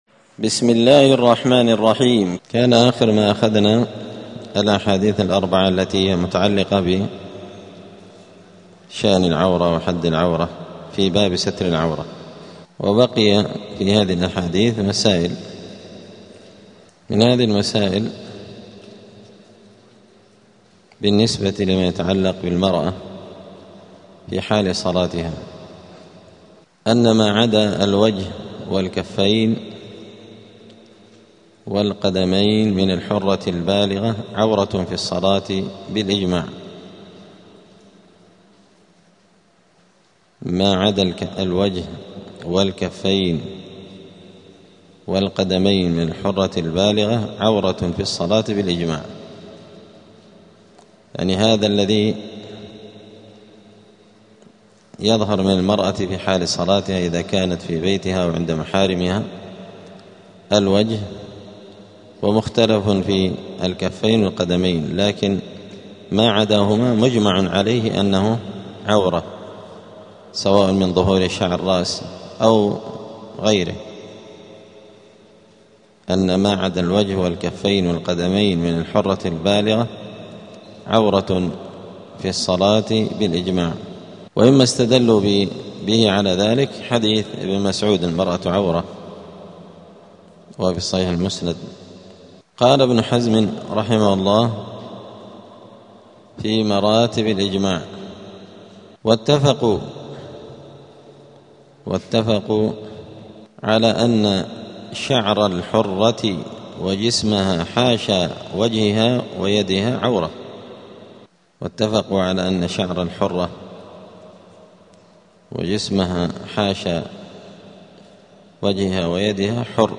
دار الحديث السلفية بمسجد الفرقان قشن المهرة اليمن
الدروس الأسبوعية